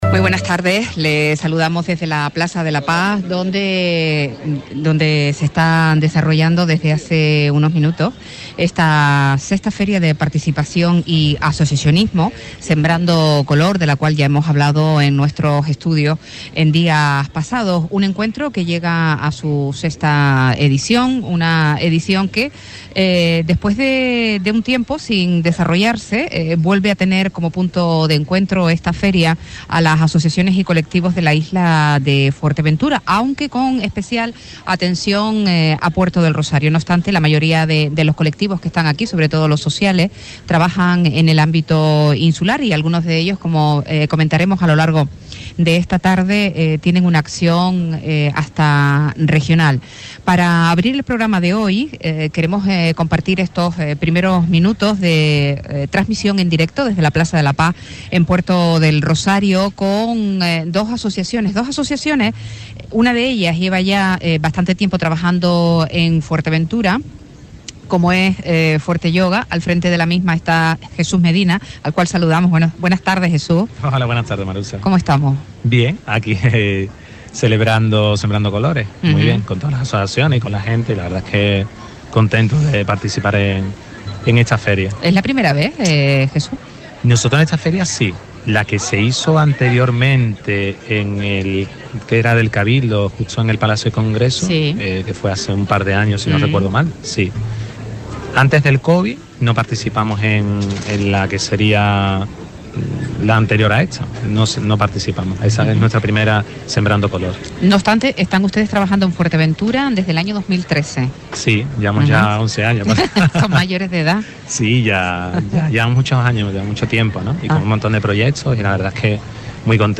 Abraza la Vida y FuerteYoga en la IV Feria de Asociacionismo
Entrevistas